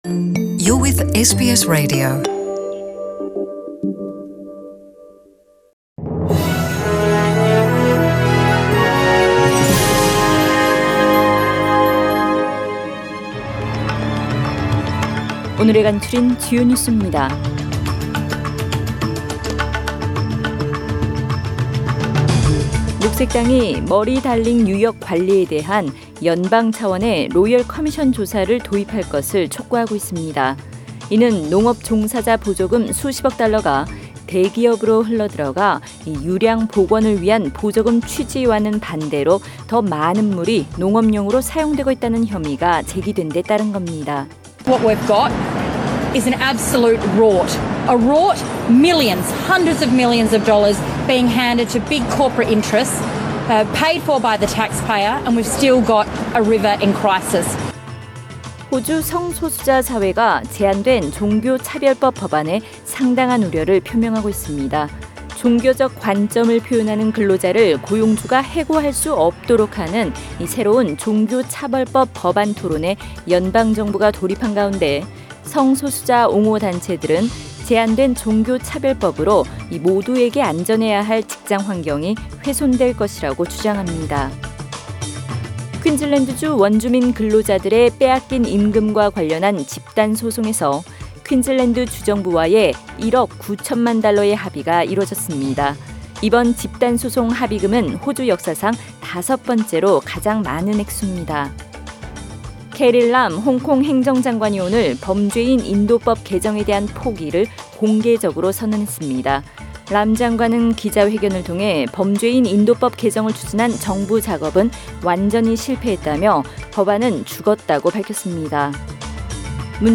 SBS 한국어 뉴스 간추린 주요 소식 – 7월 9일 화요일